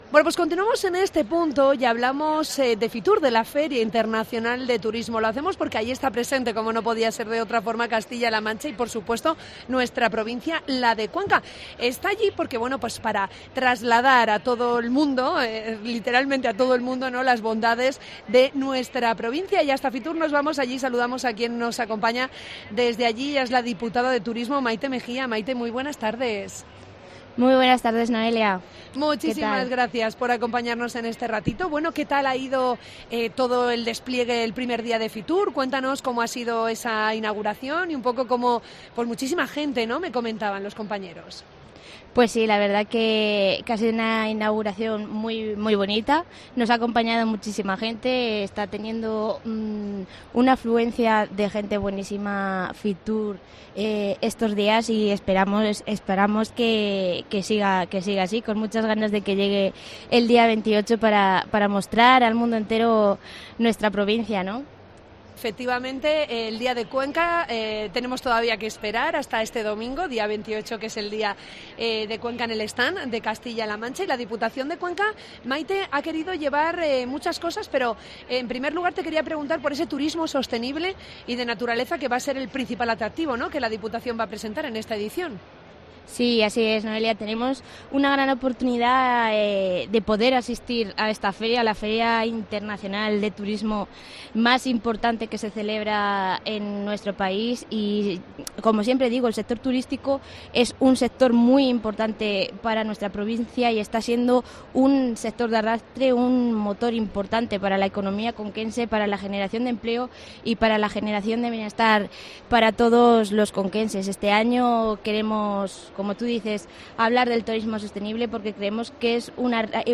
Entrevista con la diputada de Turismo, Mayte Megía